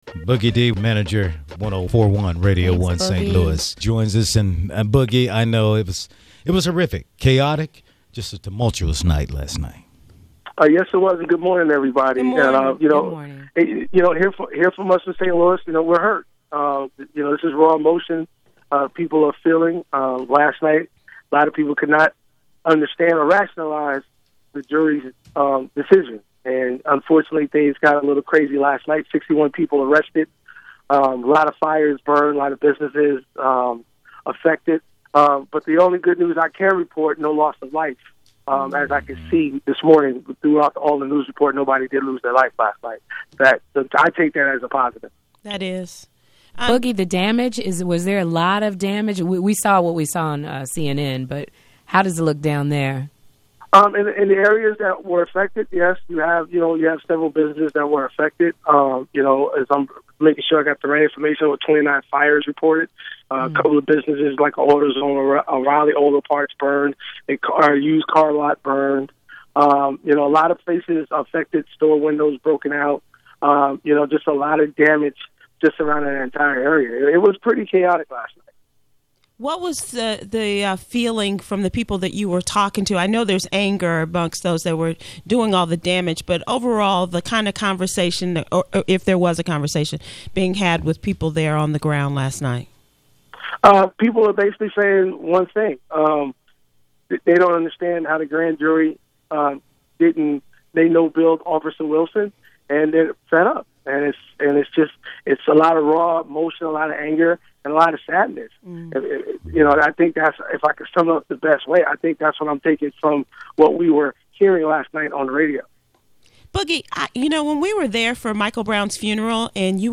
LIVE from Ferguson